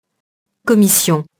commission [kɔmisjɔ̃]